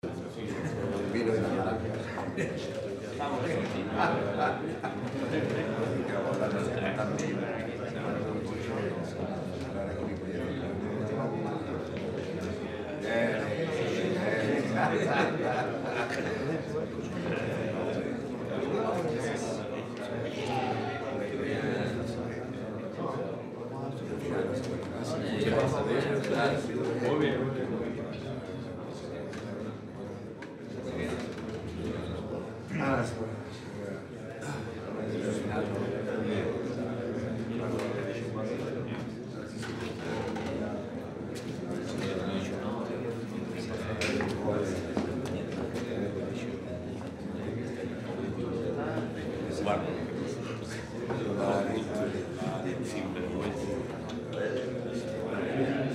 MURMULLO GENTEGENTE
Tonos EFECTO DE SONIDO DE AMBIENTE de MURMULLO GENTEGENTE
Murmullo_GenteGente.mp3